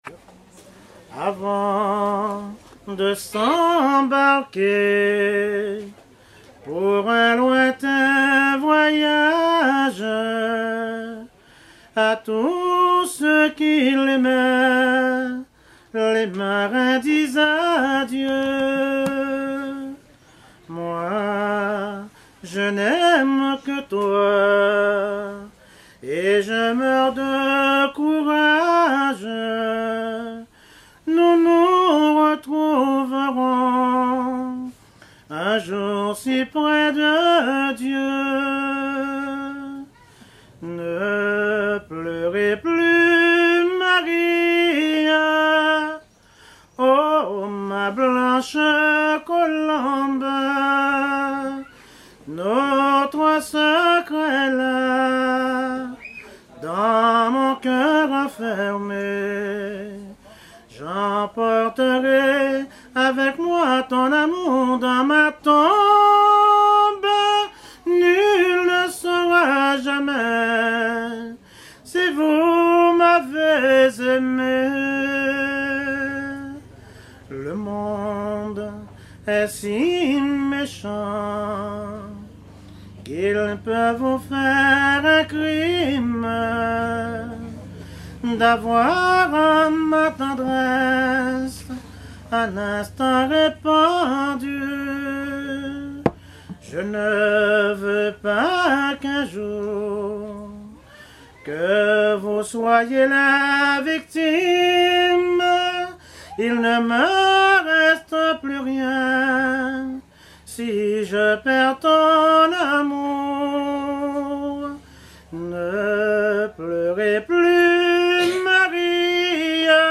Genre strophique
chansons anciennes recueillies en Guadeloupe
Pièce musicale inédite